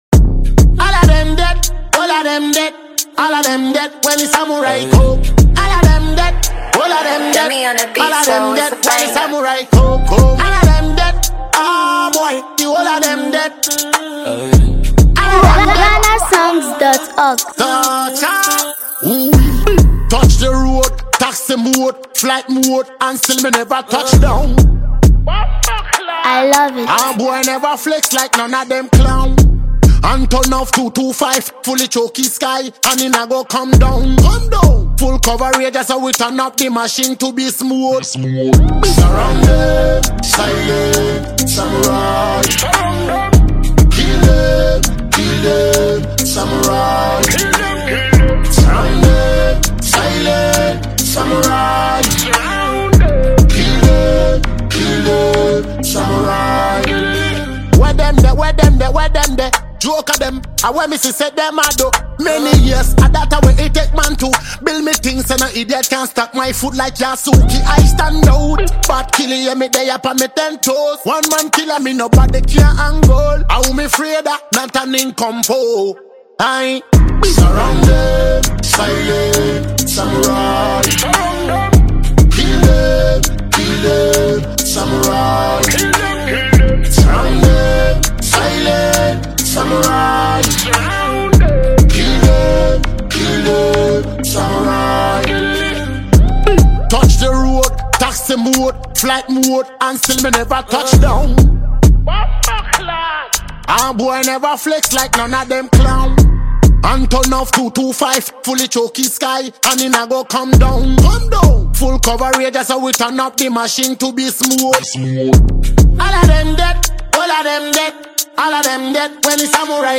smooth rhythm
With clean production and meaningful delivery